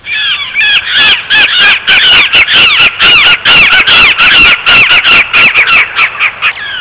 chimango.wav